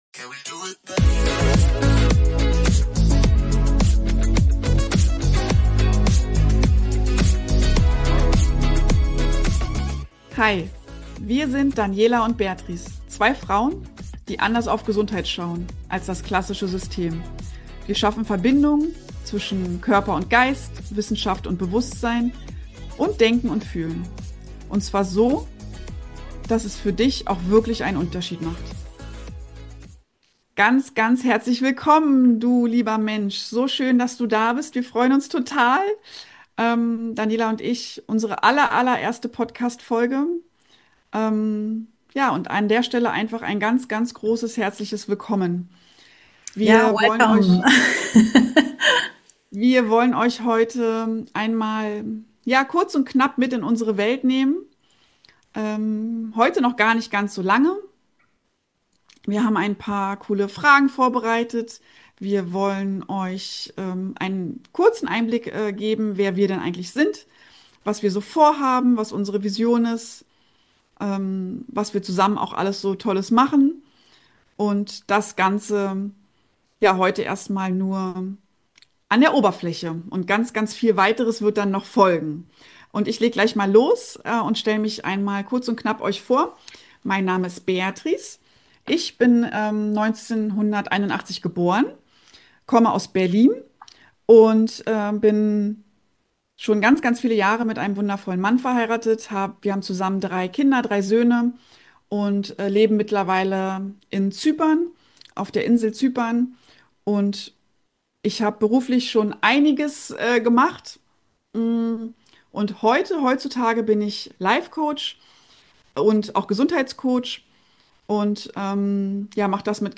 zwei Frauen mit unterschiedlichen Wegen, aber einer gemeinsamen Vision.